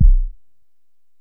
(KICK) Culture National Anthem.wav